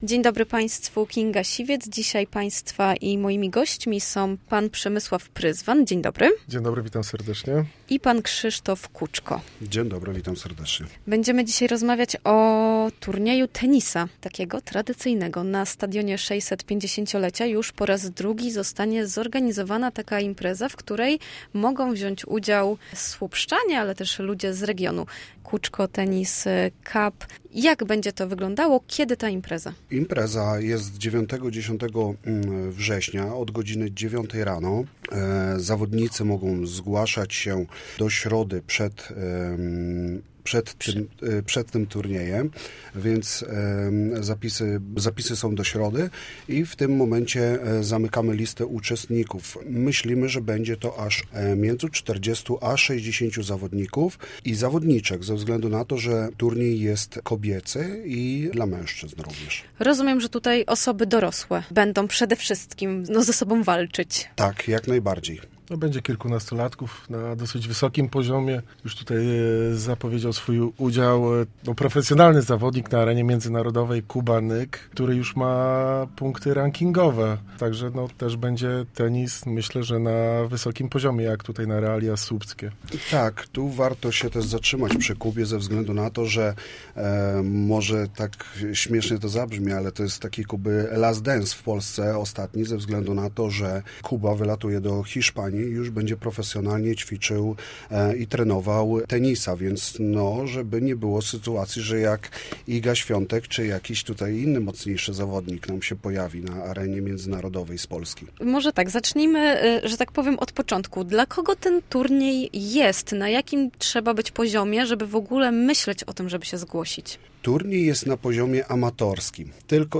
Dziś na antenie Studia Słupsk gościli organizatorzy wydarzenia